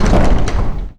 rattle1.wav